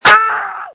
JUST SCREAM! Screams from December 5, 2020
• When you call, we record you making sounds. Hopefully screaming.
You might be unhappy, terrified, frustrated, or elated.